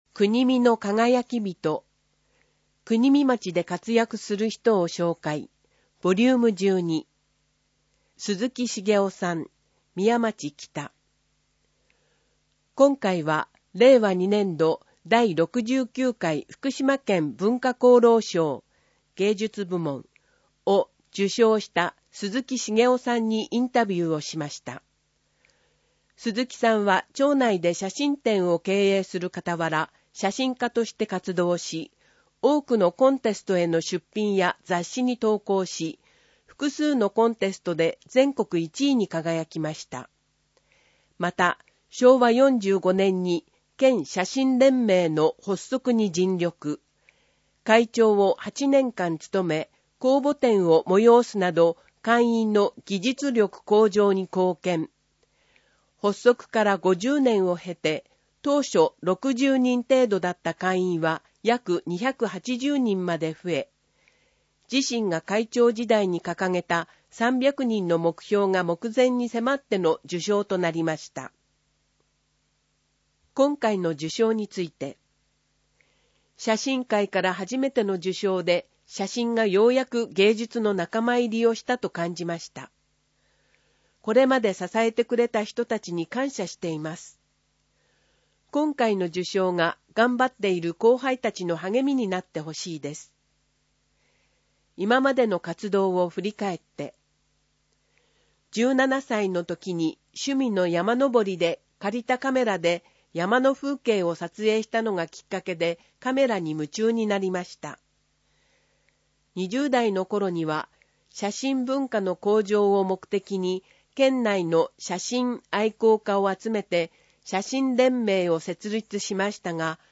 ＜外部リンク＞ 声の広報 広報紙の内容を音声で提供しています。